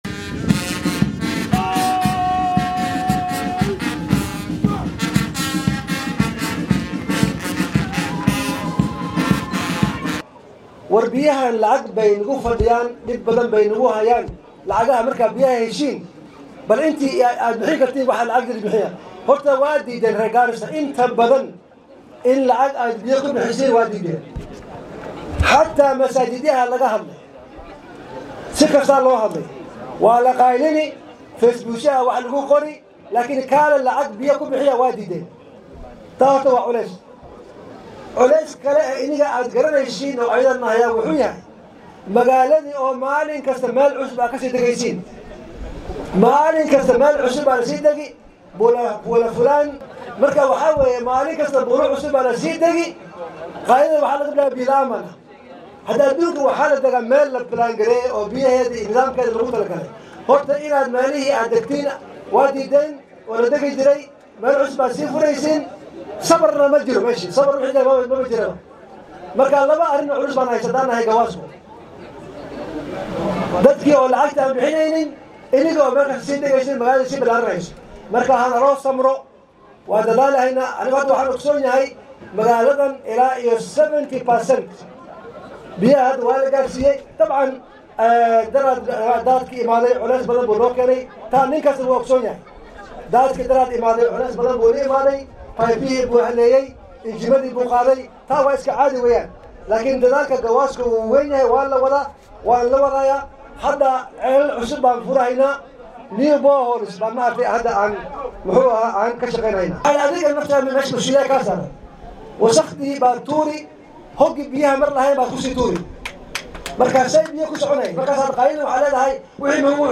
DHAGEYSO:Barasaabka Garissa oo bulshada ismaamulkaasi ugu baaqay in lacagta biyaha iska bixiyaan